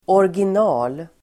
Uttal: [årgin'a:l]